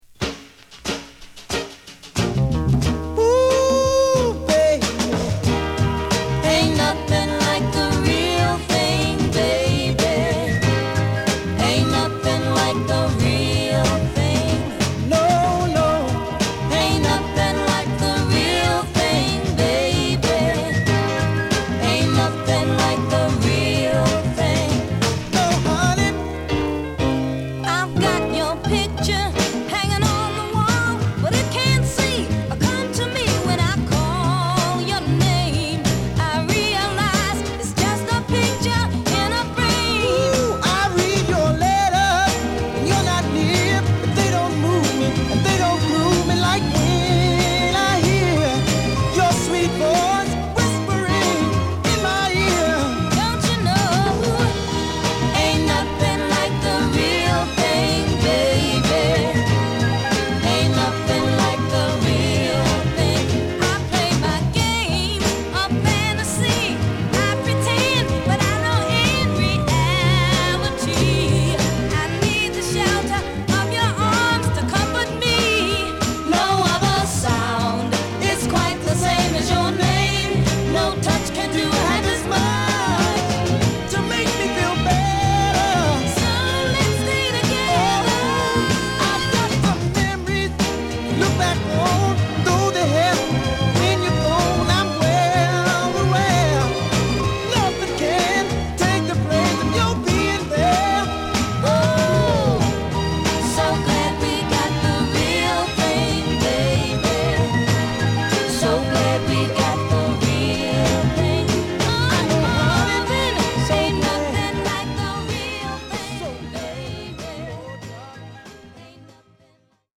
爽快なフルートやストリングスがハツラツとした爽やかさを後押しする